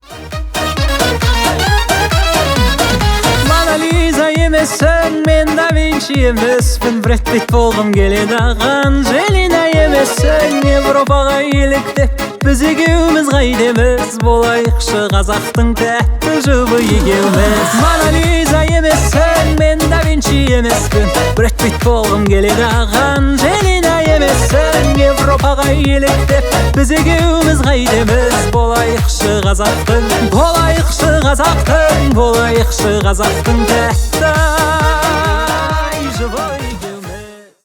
Танцевальные
весёлые